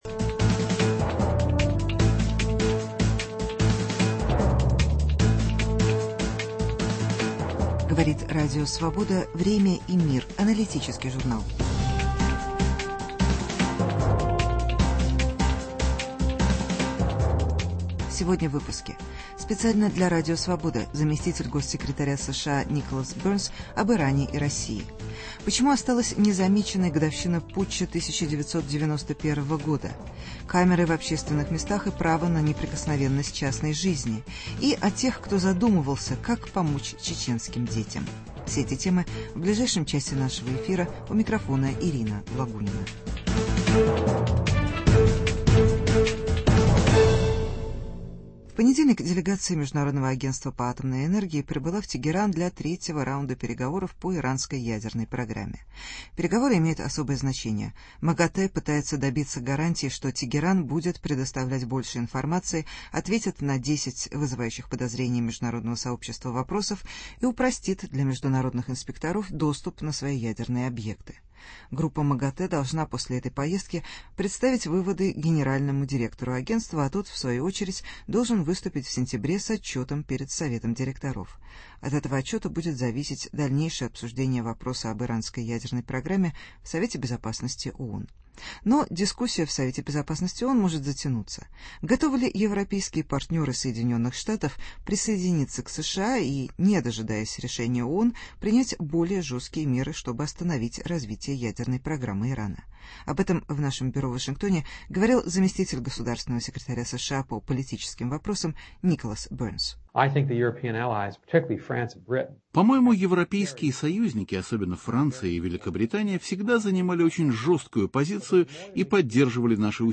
Политика США по отношению к Ирану. Интервью с заместителем госсекретаря Николасом Бёрнсом.